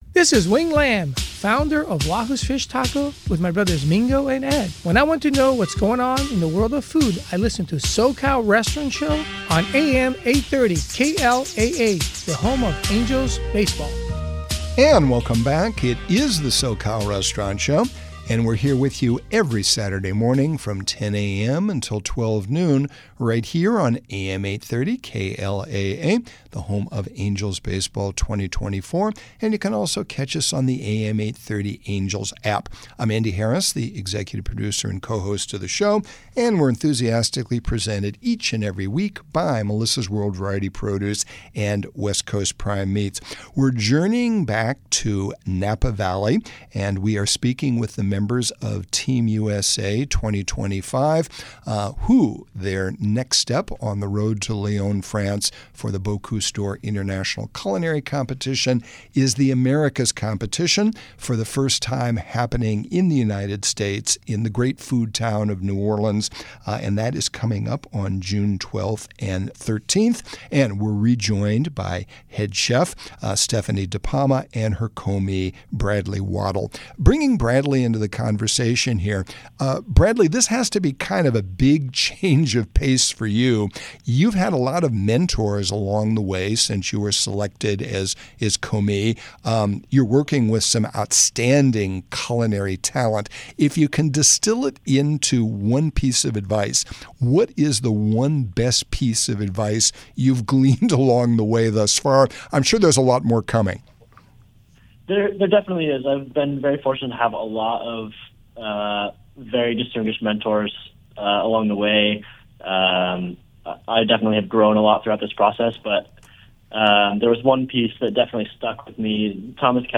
We’re now continuing the catch-up conversation